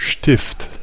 Ääntäminen
IPA: /ˈʃtɪft/